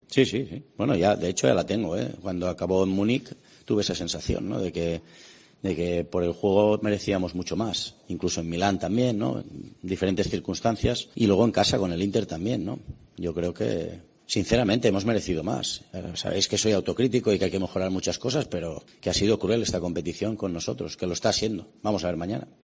"Tenemos una pequeña esperanza, pero lo tenemos difícil. No depender de ti mismo genera dudas. Estamos en una situación muy incómoda, hay muchos números de que no pasemos, pero el fútbol tiene estas cosas. A veces no gana el que se lo merece y hay imprevistos. Lo hemos tenido en nuestra mano y podríamos estar dependiendo de nosotros", dijo en rueda de prensa.